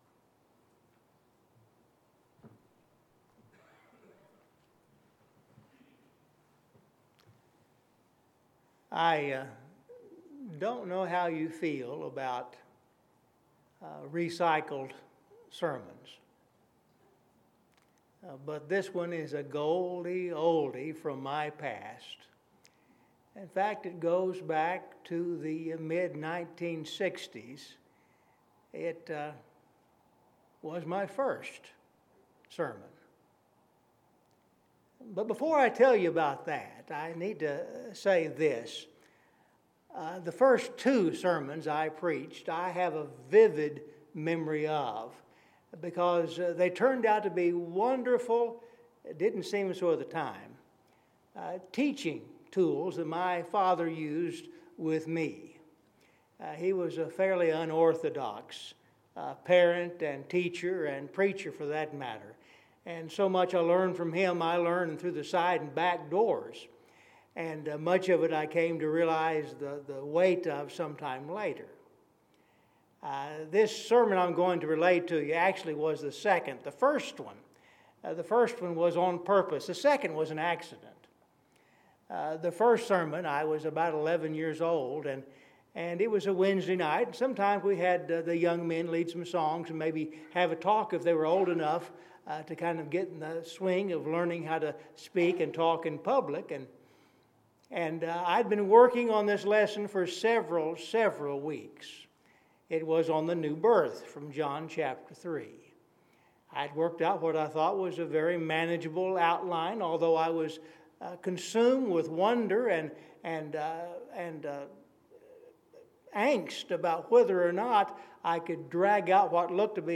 One of My First Sermons – PM
OneOfMyFirstSermons.mp3